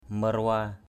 /mə-ra-wa:/ (d.) kỳ đà đen = iguane, varan noir = black lizard.